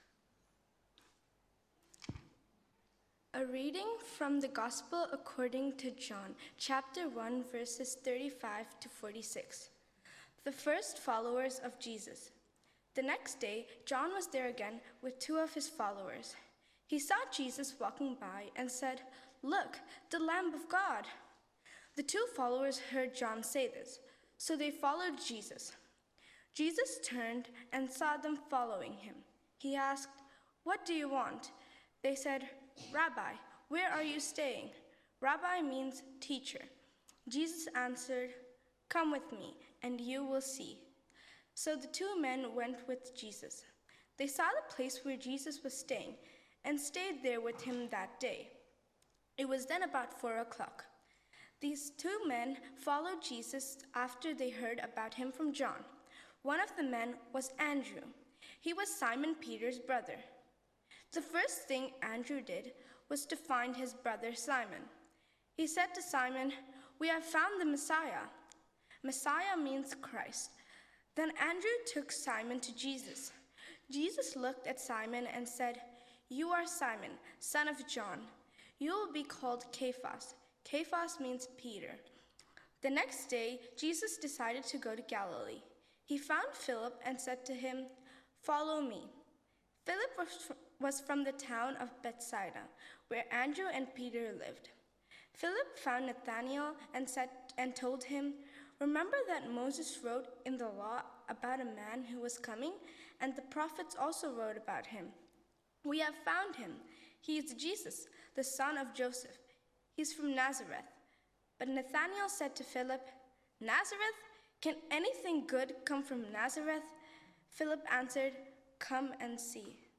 Sermon – August 10, 2025 (Holiday Bible Club 20205 Kick off service)